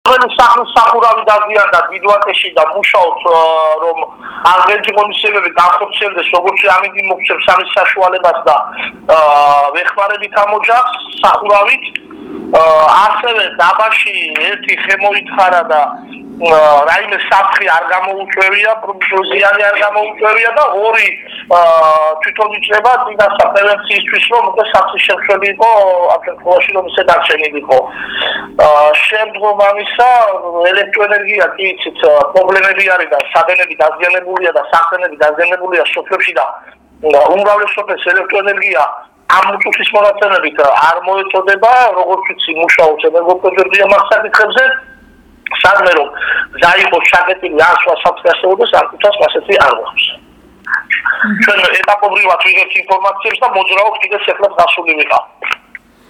ხარაგაულის მუნიციპალიტეტის მერის პირველი მოადგილე ვარლამ ჭიპაშვილი, რამდენიმე უპასუხო ზარის შემდეგ, „ჩემი ხარაგაულის“ რედაქციას თავად დაგვიკავშირდა და ხარაგაულის მუნიციპალიტეტში ძლიერი ქარით გამოწვეული დაზიანებების შესახებ ინფორმაცია მოგვაწოდა.